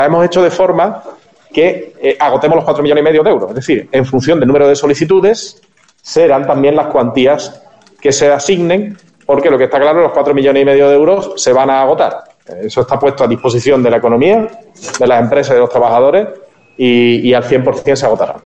En una rueda de prensa, junto a la delegada de Reactivación Económica e Innovación, Blanca Torrent, el alcalde ha destacado que dicha cantidad procede del acuerdo de los remanentes respaldado por la mayoría del Pleno antes de final de año y se destinan a "autónomos y pequeñas, medianas y microempresas de la ciudad para ayudarles a superar la crisis del Covid".